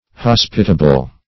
Hospitable \Hos"pi*ta*ble\, a. [Cf. OF. hospitable, LL.